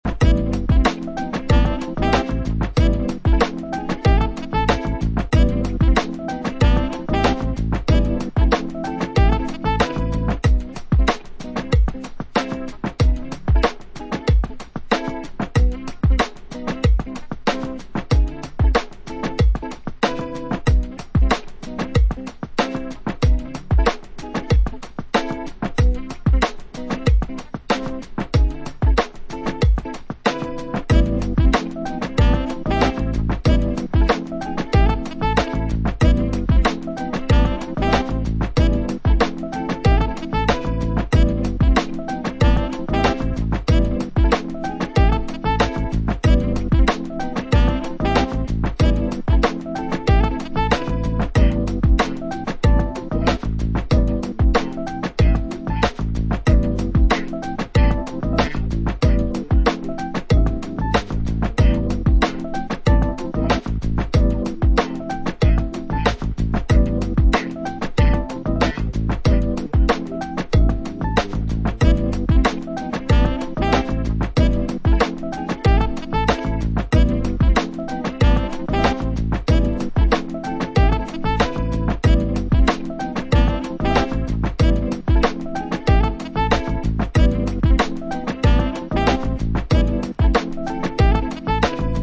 with a dark atmosphere